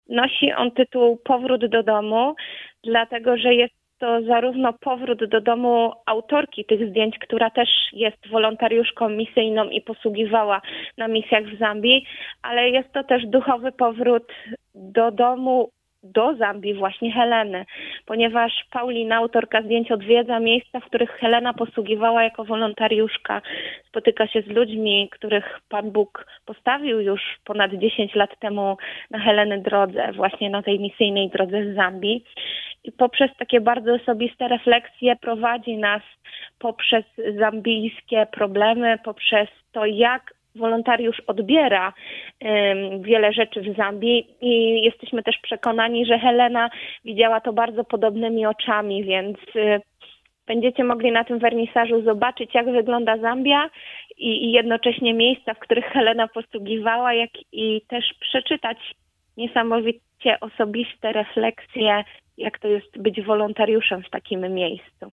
Łączymy się telefonicznie